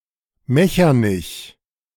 Mechernich (German: [ˈmɛçɐnɪç]
De-Mechernich.ogg.mp3